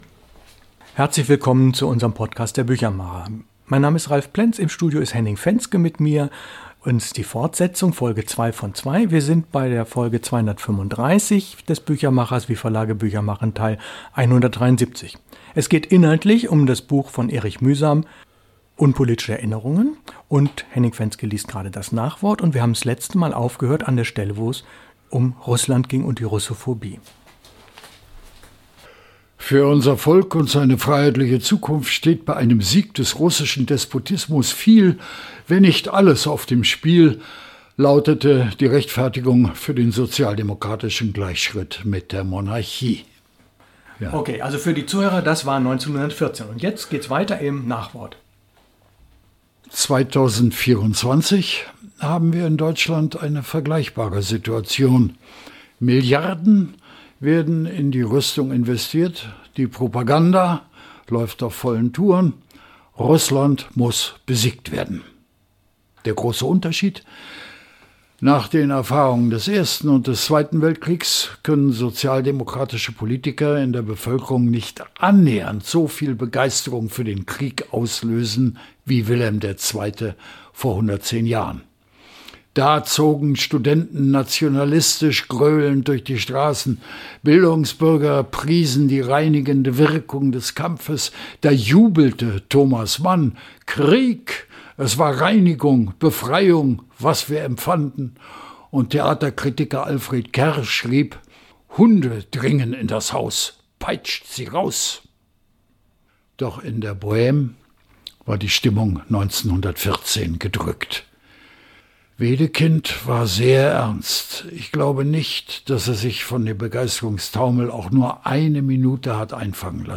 Der Büchermacher Folge 234. Henning Venske liest aus seinem Vor- und Nachwort zu Erich Mühsam: Unpolitische Erinnerungen.